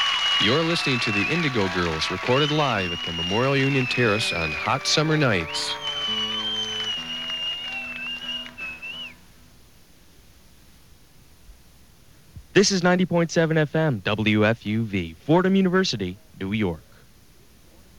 (acoustic duo show)
07. station break (0:17)